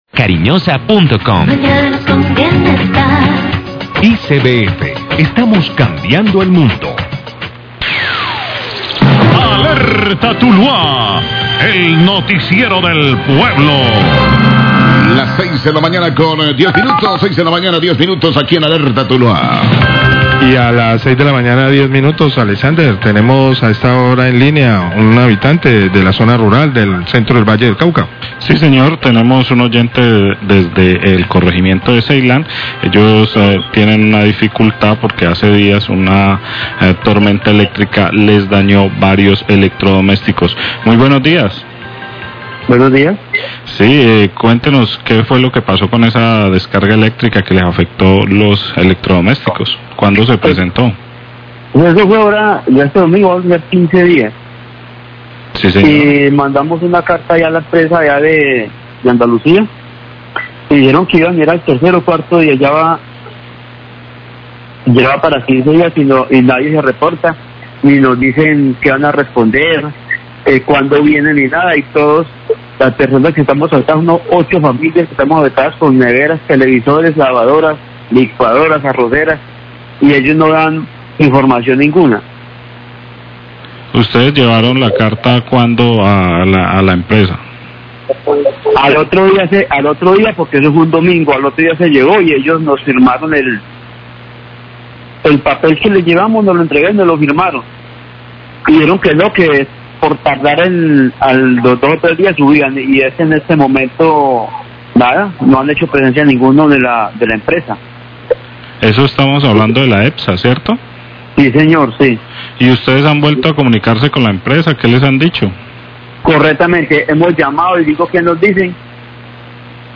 Radio
queja oyente